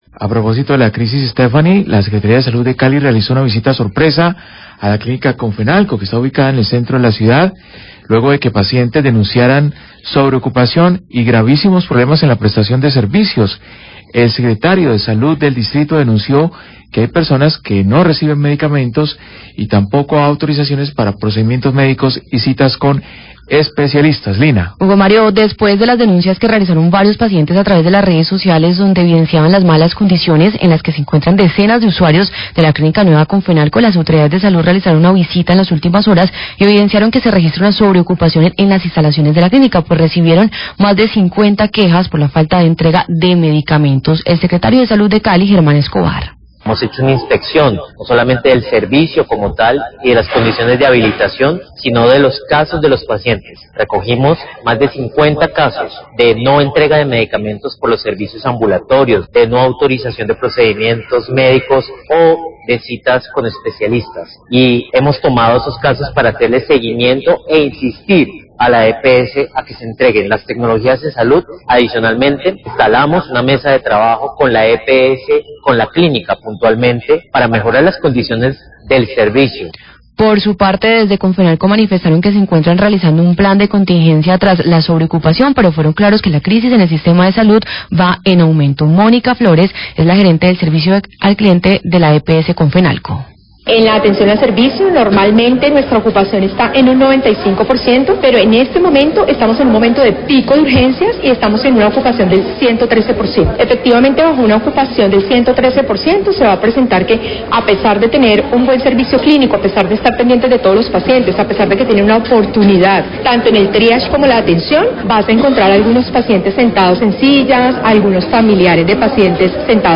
Srio. Salud Cali habla de los hallazgos tras visita sorpresa a clínica de Comfenalco
Radio